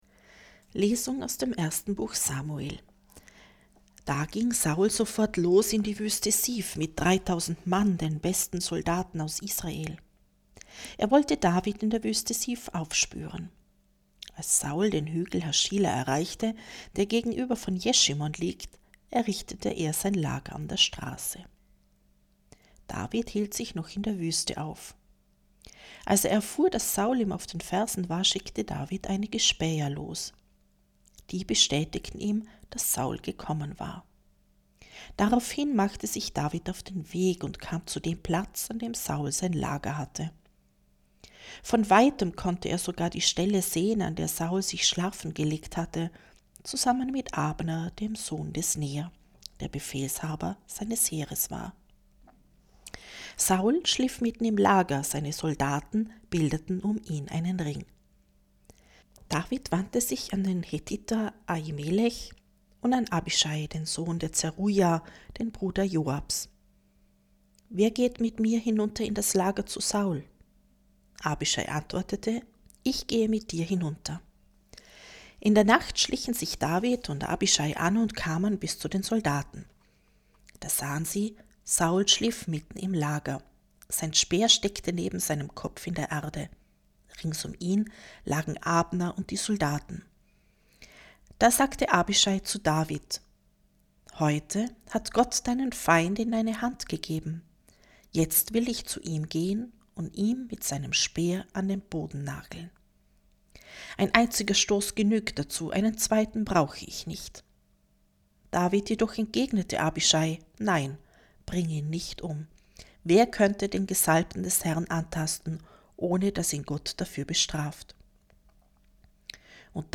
1.-Lesung-2.mp3